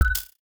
UIClick_Next Button 05.wav